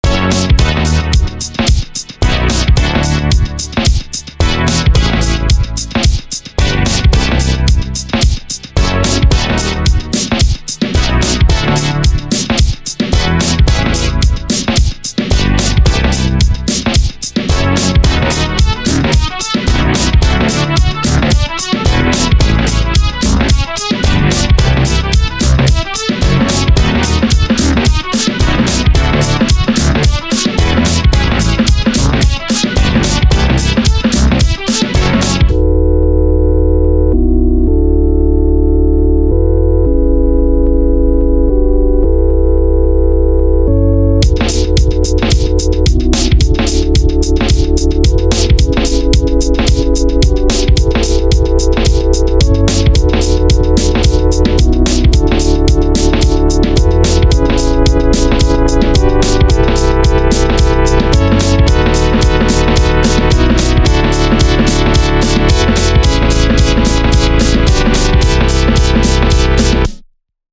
that’s a very fresh and funky beat!